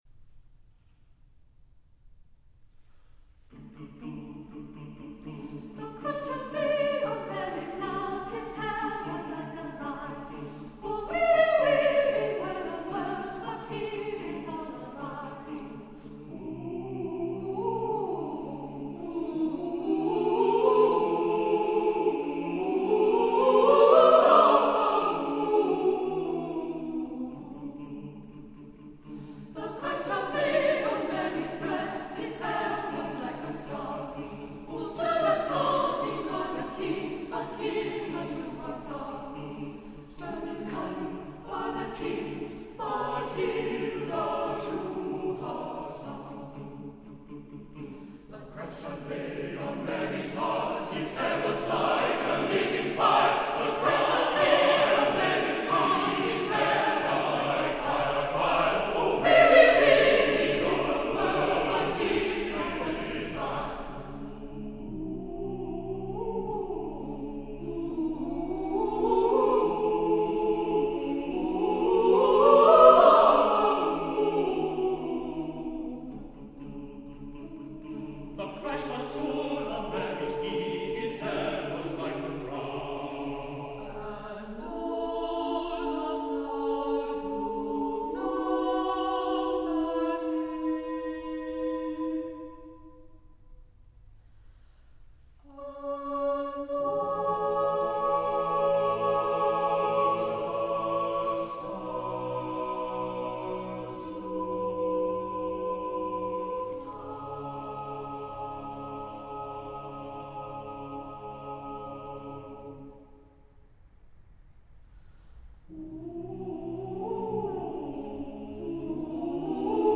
Accompaniment:      A Cappella
Music Category:      Choral